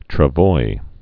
(trə-voi, trăvoi)